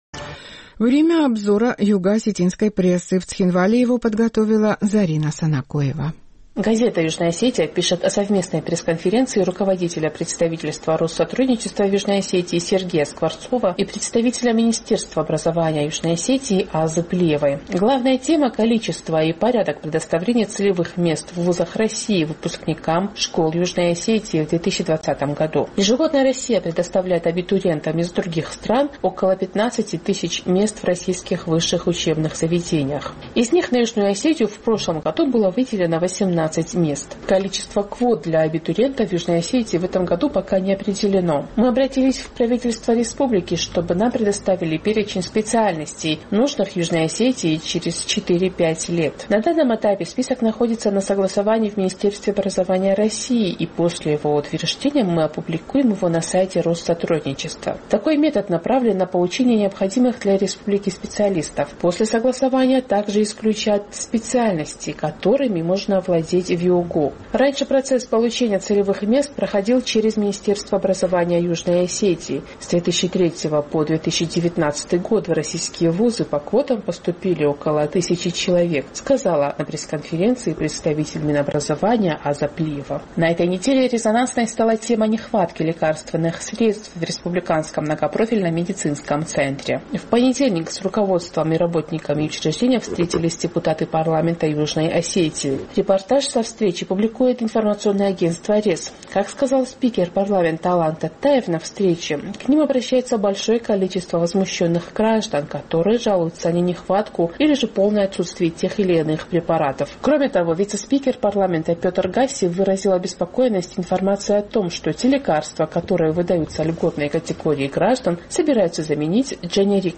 Обзор югоосетинской прессы